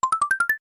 Sound effect from Super Mario RPG: Legend of the Seven Stars
Self-recorded using the debug menu
SMRPG_SFX_Bonus_Flower.mp3